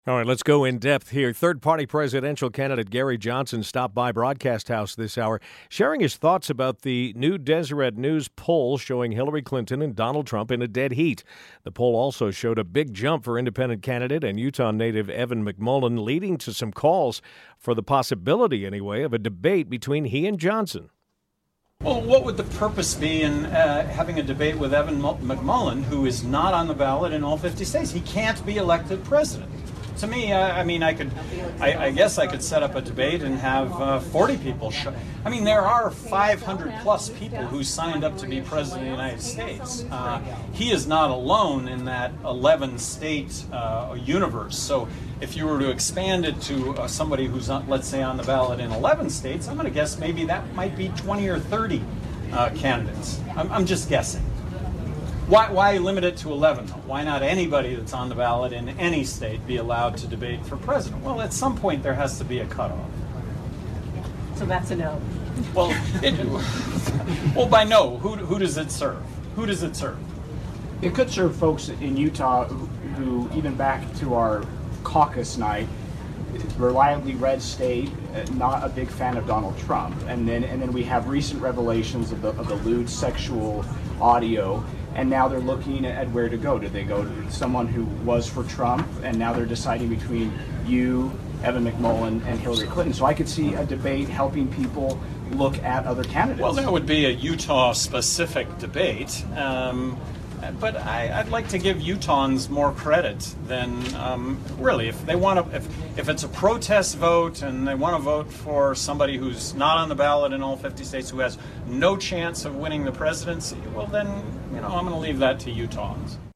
Libertarian Presidential nominee Gary Johnson stopped by KSL to talk up his platform. But he was asked if he would be interested in a debate with Independent candidate Evan McMullin.